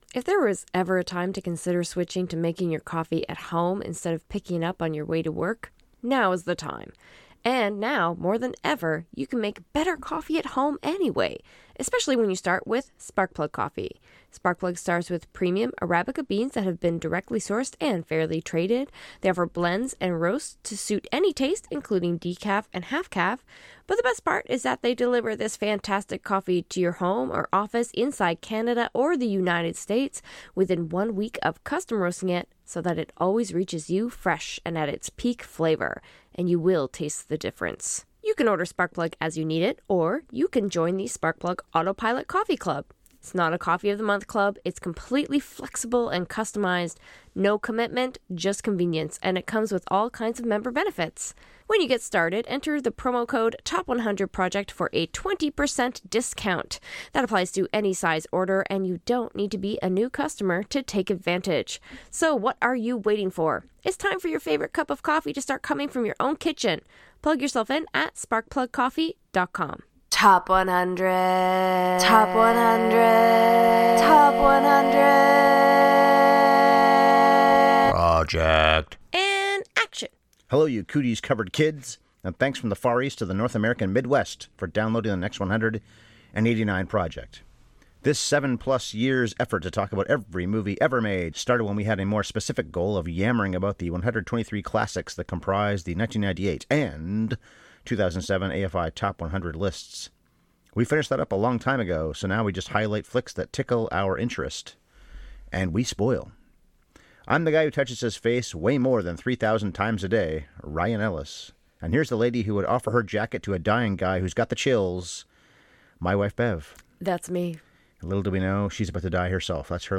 Rated R. We got a little roughy mouthy.